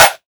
SOUTHSIDE_percussion_box_of_stuff.wav